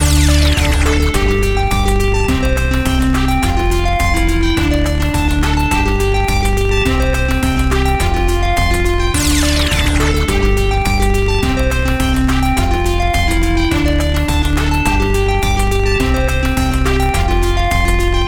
EDMのようなノリノリ感をずっとループできます。
かっこいい わくわく 不思議 楽しい